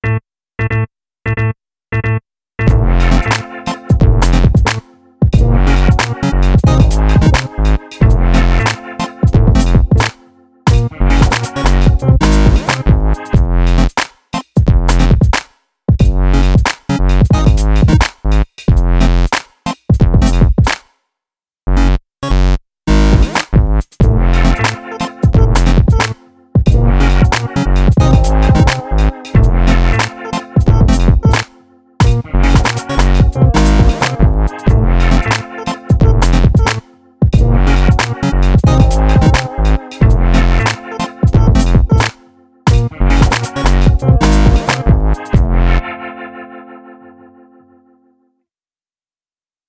Weird quirky oddball groove with sliced 8-bit pieces.